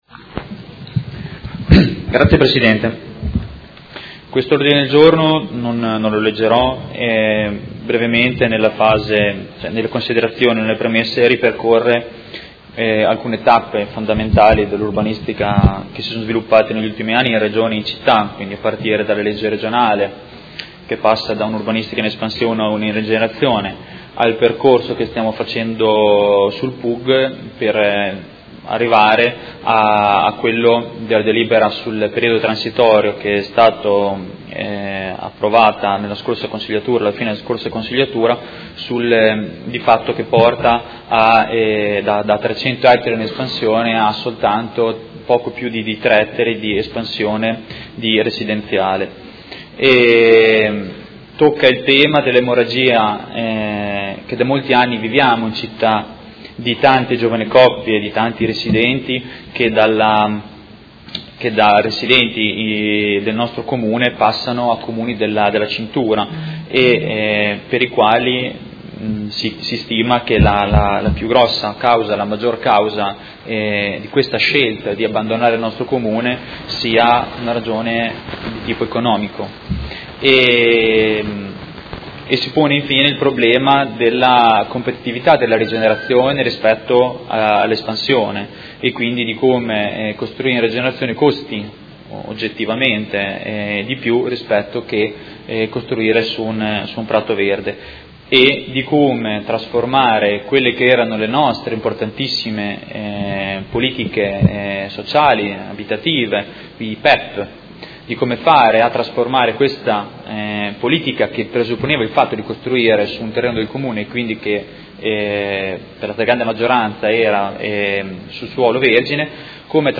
Diego Lenzini — Sito Audio Consiglio Comunale